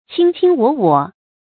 卿卿我我 qīng qīng wǒ wǒ 成语解释 形容男女相爱，十分亲昵，情意绵绵。